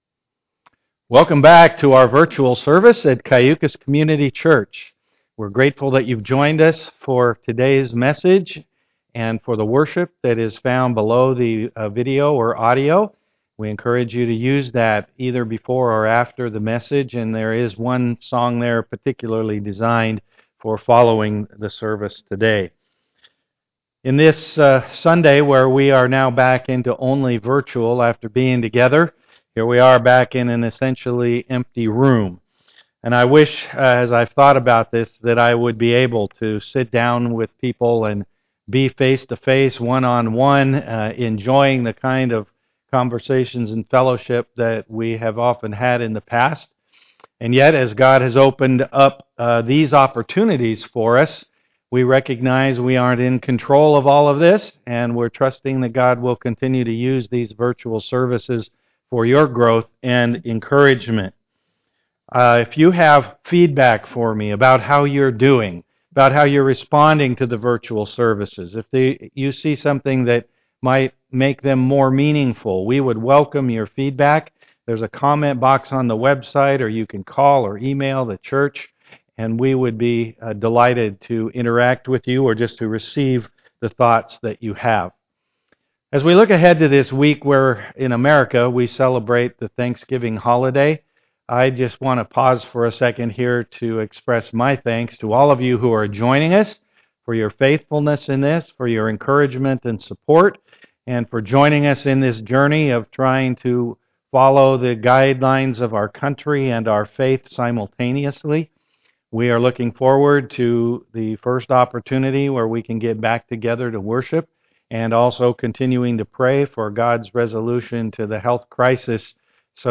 Passage: Exodus 18 Service Type: am worship